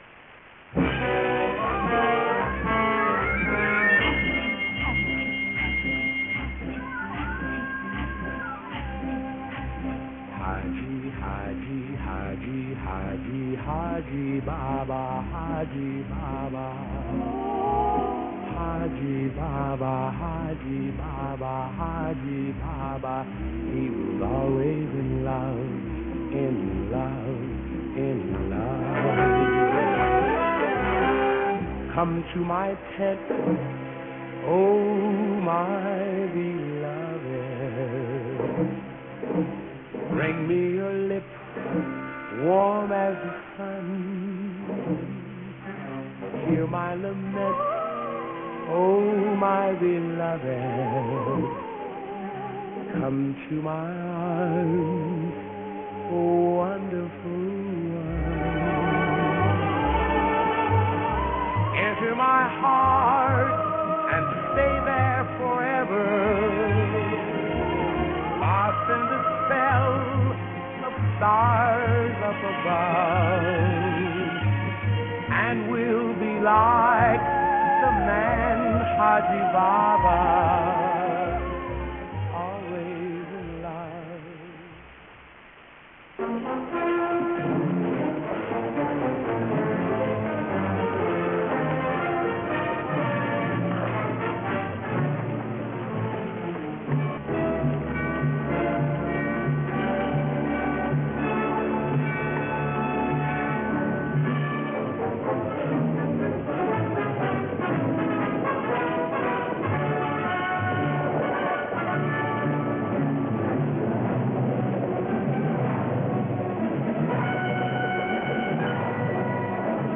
Musica semi-western
Original track music